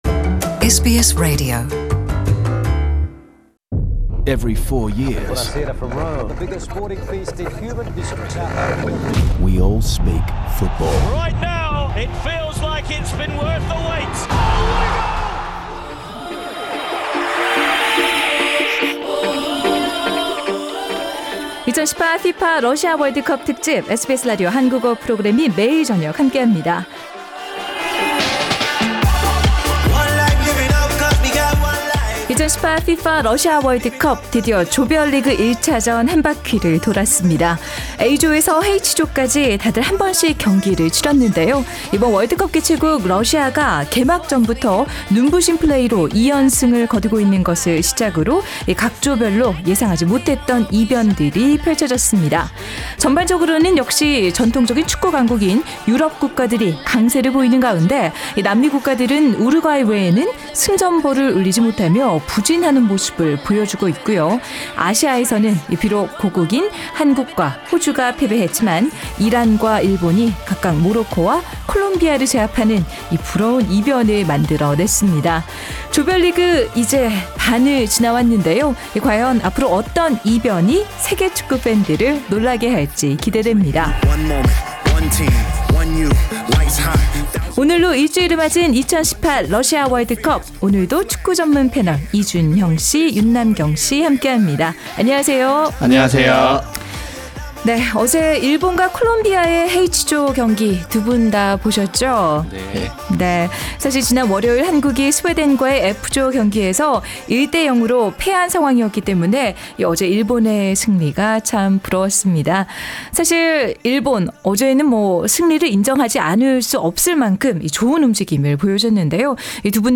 The full World Cup Panel discussion is available on the podcast above.